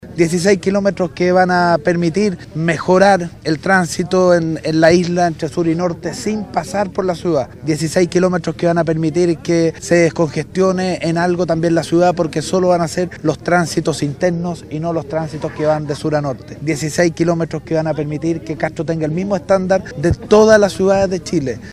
Al respecto, el exministro Undurraga detalló que el primer contrato fue adjudicado a la empresa Besalco el 17 de agosto de 2015, por más de 24 mil millones de pesos.